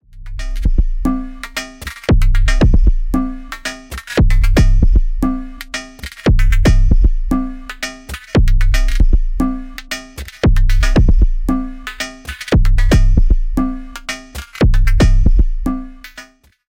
Generative sampling drum studio
Battalion delivers an unparalleled auditory experience by combining the tonalities of classic synthesizers with the sampling capabilities of modern drum machines.